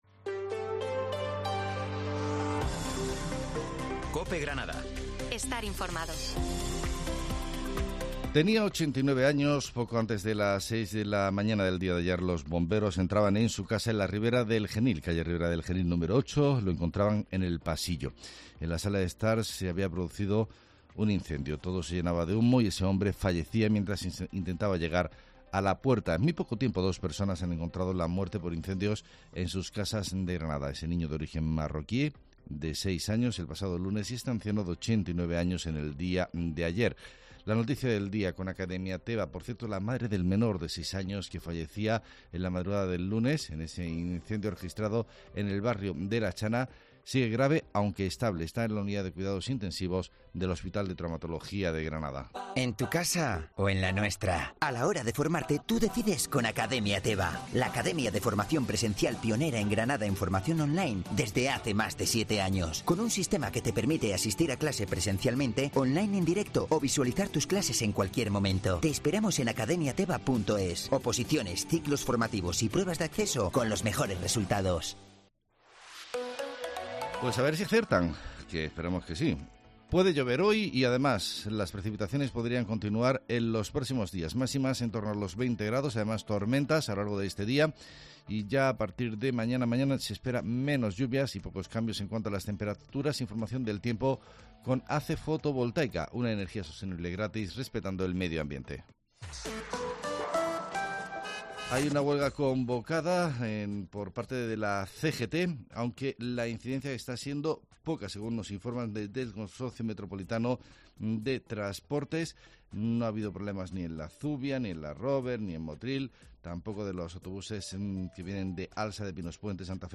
HERRERA EN COPE GRANADA INFORMATIVO DEL 18 DE MAYO DE 2023